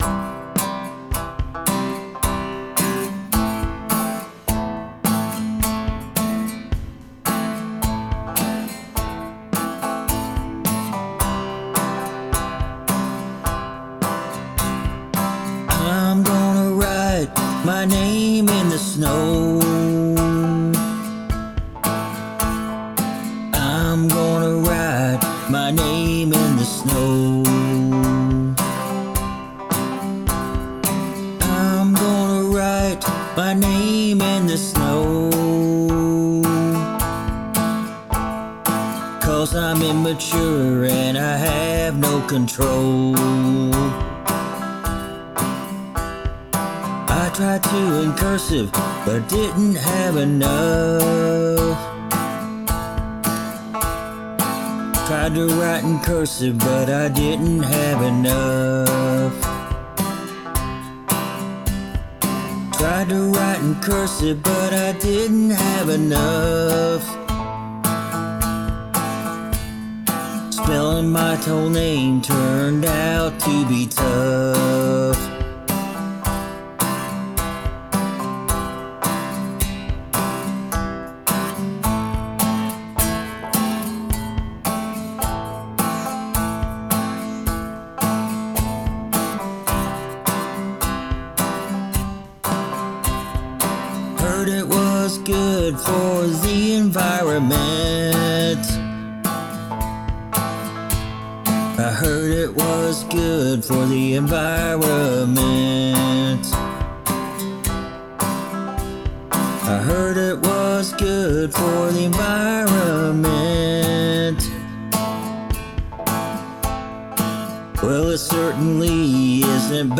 Regardless, catchy tune.
Very funny song, well delivered.
Love the banjo and the vox